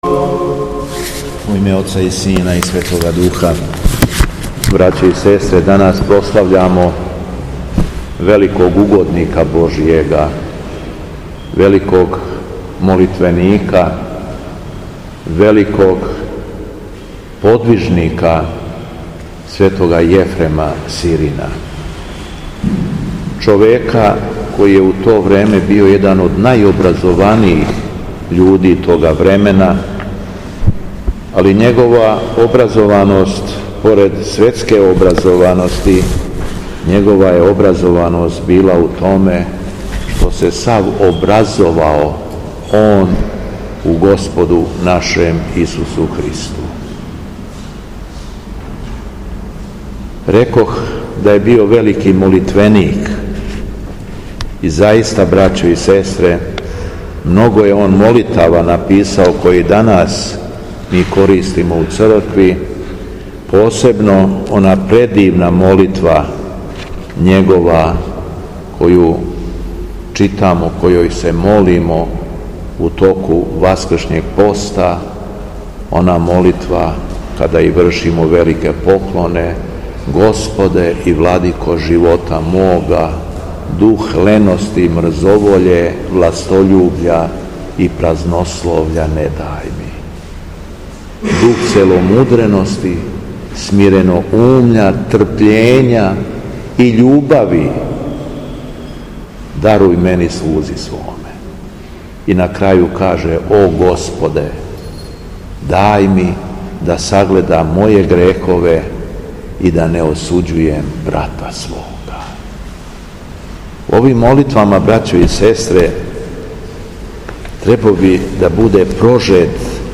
Беседа Његовог Високопреосвештенства Митрополита шумадијског г. Јована
У понедељак, недеље митара и фарисеја, када наша Света Црква прославља светога Јефрема Сирина, Његово Високопреосвештенство служио је свету архијерејску литургију у храму Светога Саве у крагујевачком насељу Аеродром.